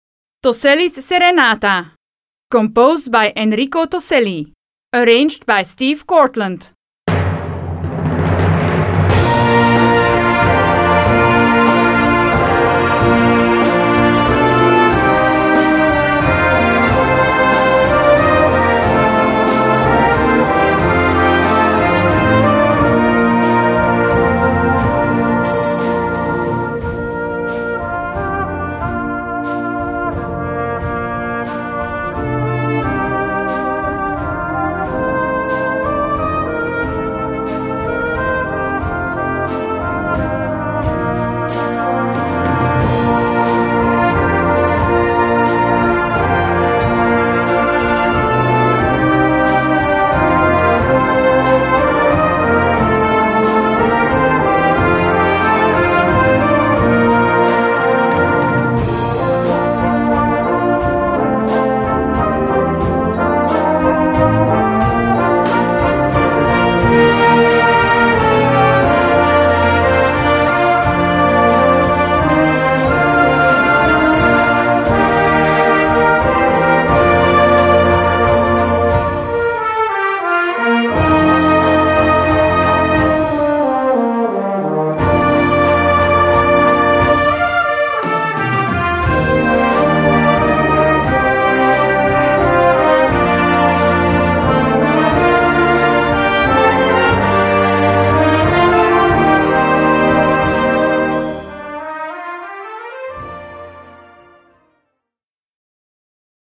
Gattung: Classics up to Date
A4 Besetzung: Blasorchester Zu hören auf